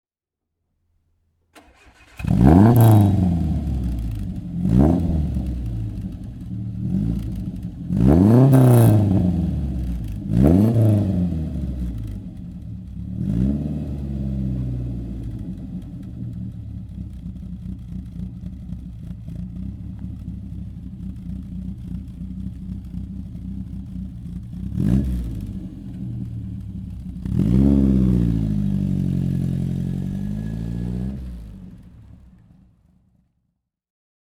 Lotus Elan S4 Coupé (1969) - Starten und Leerlauf
Lotus_Elan_S4_1969.mp3